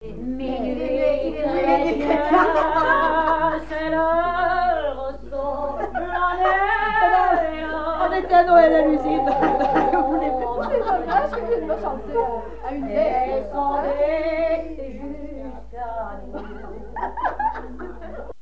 Noël
Pièce musicale inédite